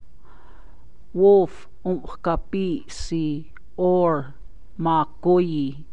Wolf
98920-wolf.mp3